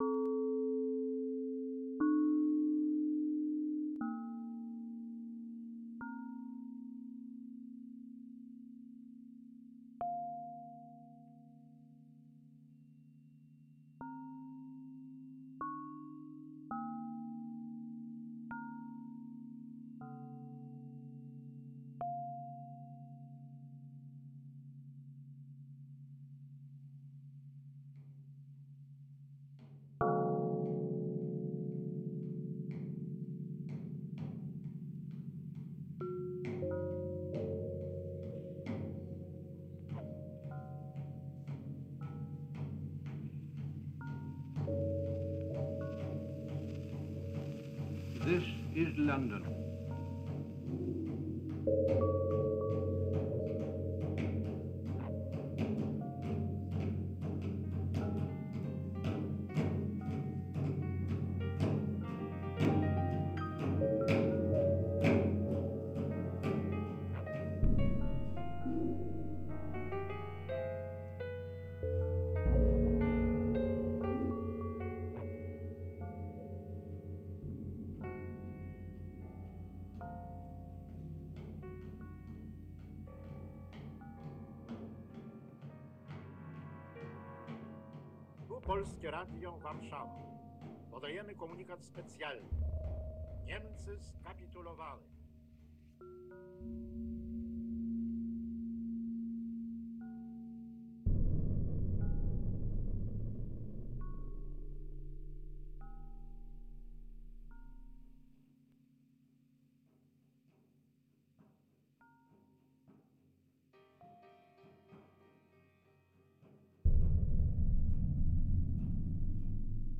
This is a stereo re-composition